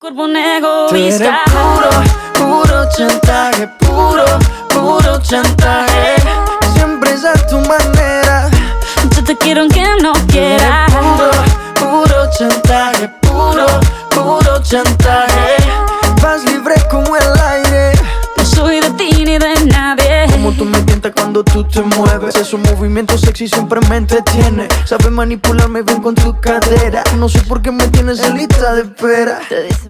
• Latin Pop